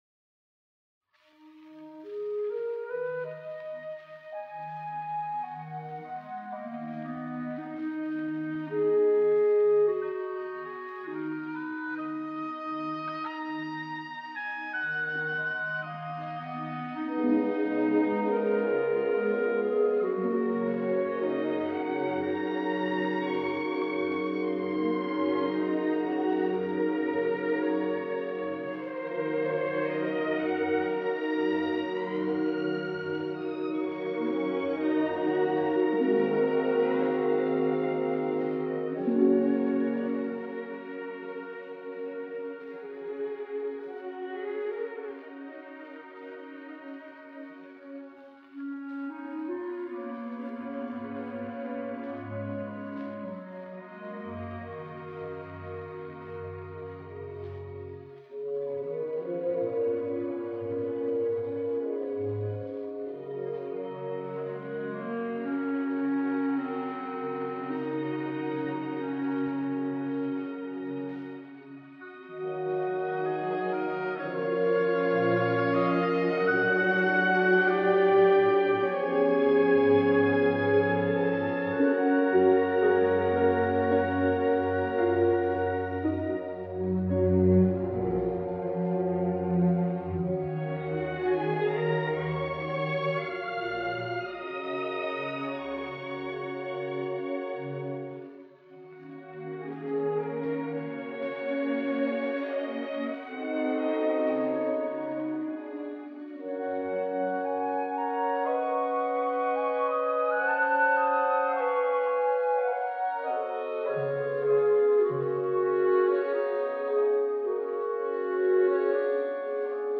Concierto para Piano Nº1 en Mi bemol mayor. Allegro/Irmelin.